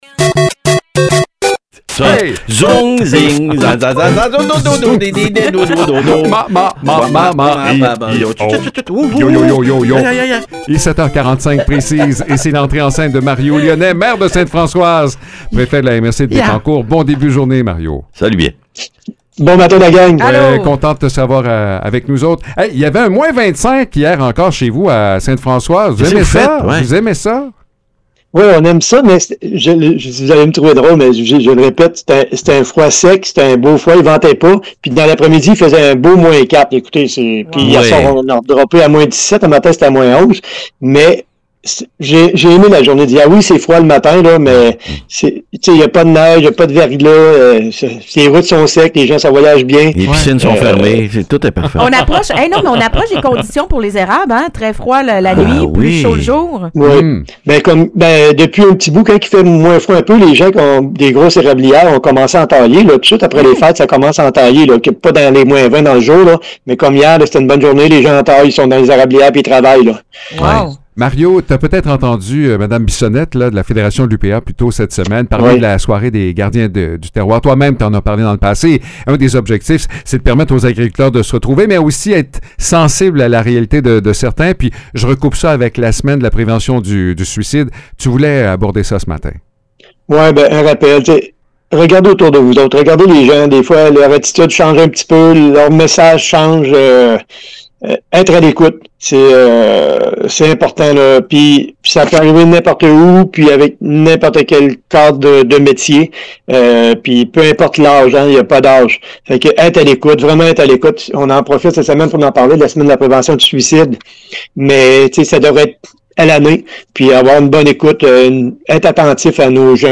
Mario Lyonnais, maire de Sainte-Françoise et préfet de la MRC de Bécancour, profite de la Semaine de la prévention du suicide pour rappeler l’importance de briser le silence et de tendre la main à ceux qui en ont besoin.